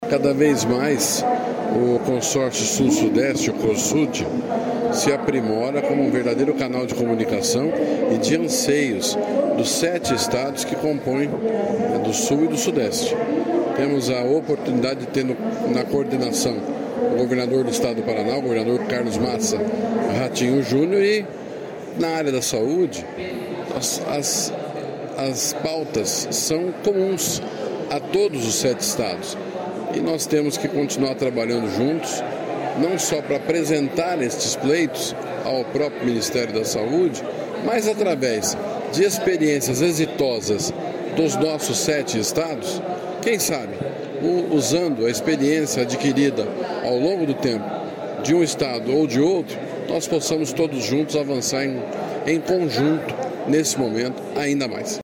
Sonora do secretário Estadual da Saúde, Beto Preto, sobre os Grupos de Trabalhos da reunião do Cosud em São Paulo